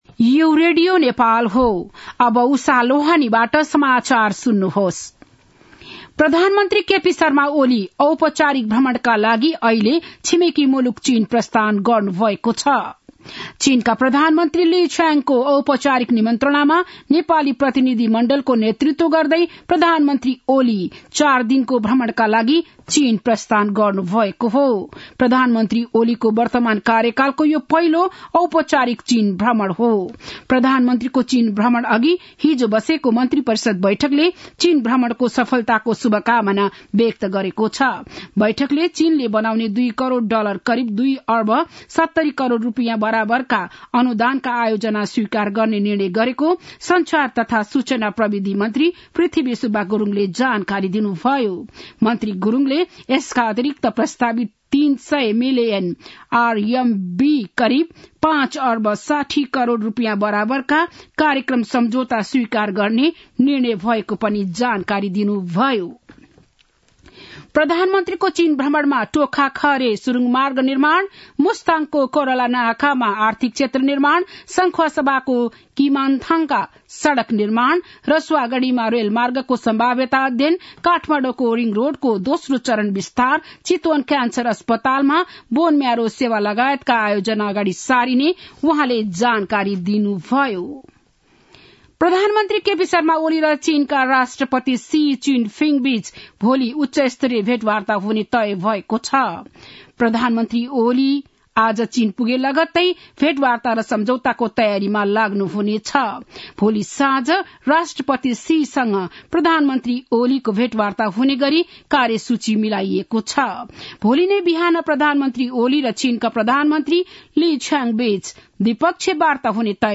बिहान ११ बजेको नेपाली समाचार : १८ मंसिर , २०८१
11-am-nepali-news-1-1.mp3